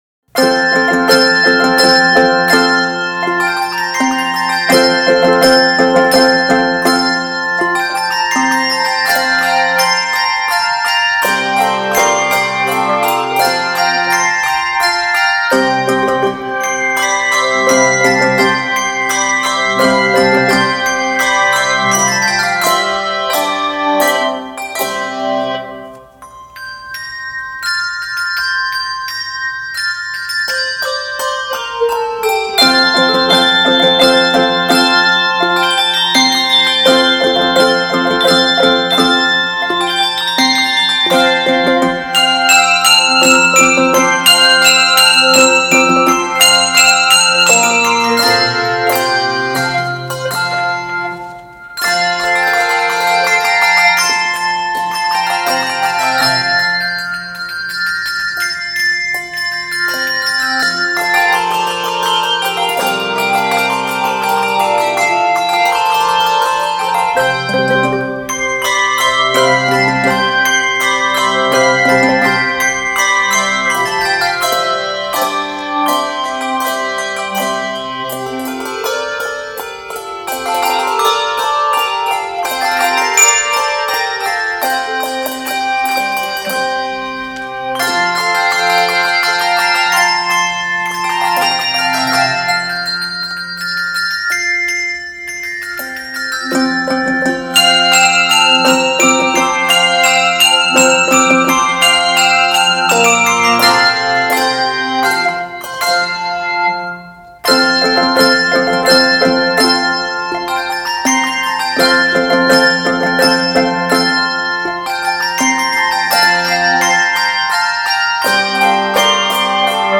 Key of F Major.